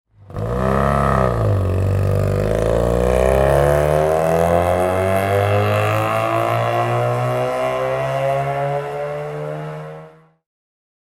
Mazda Cosmo 110 S (1969) - Start am Berg